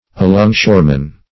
Alongshoreman \A*long"shore`man\, n.
alongshoreman.mp3